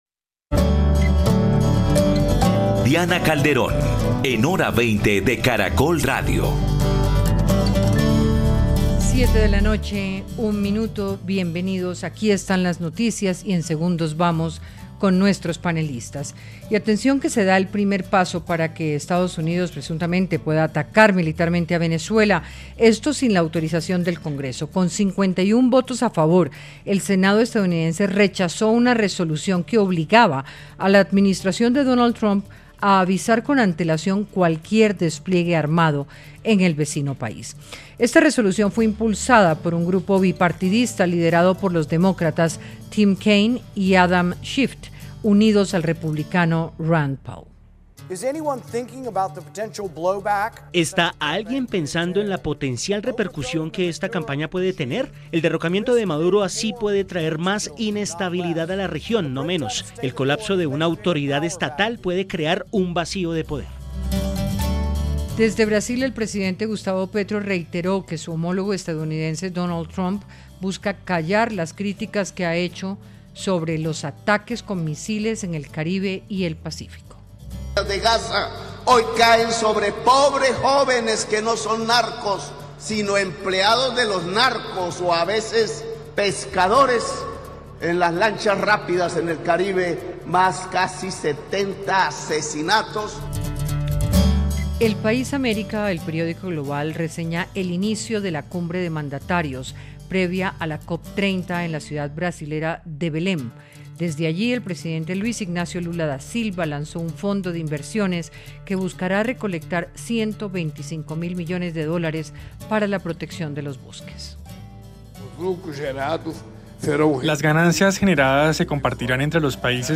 Cinco expertos, investigadores y víctimas de la toma del Palacio de Justicia analizaron los hechos, verdades y narrativas que se quieren imponer desde distintos sectores a 40 años del Holocausto del Palacio.